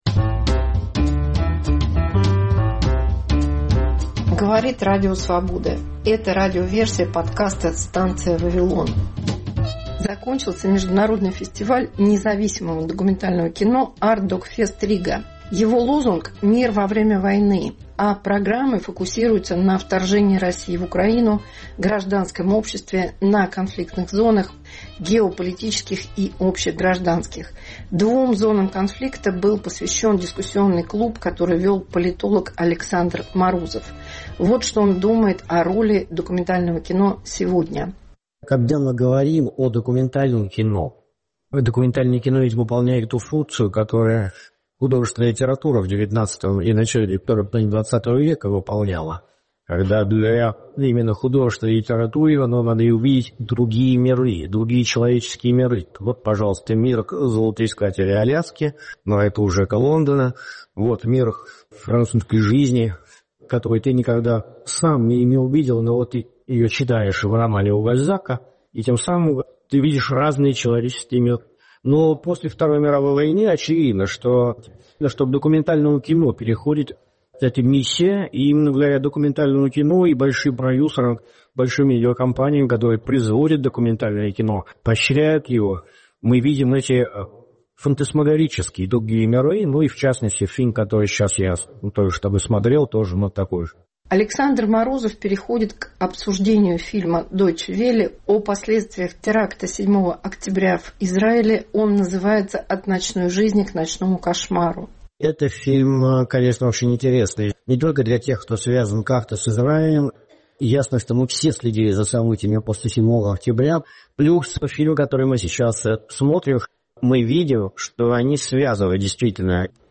Дискуссия на Artdocfest/Riga.